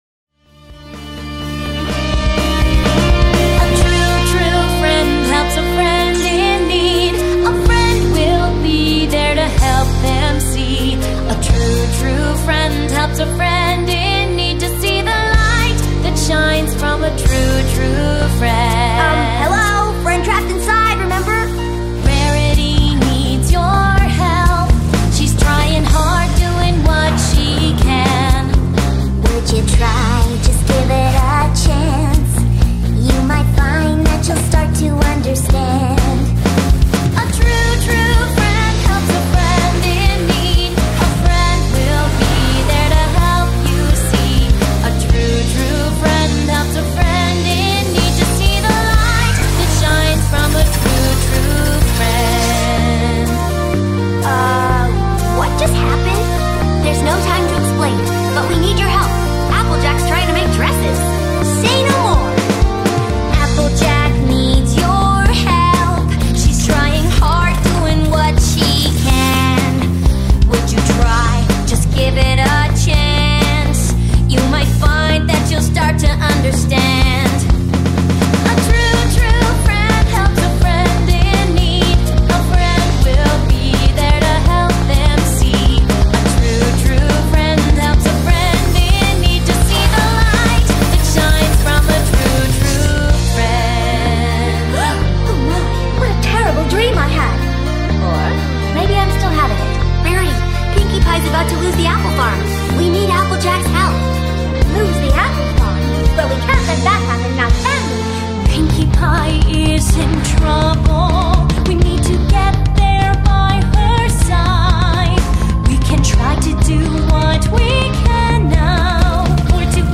cover
genre:rock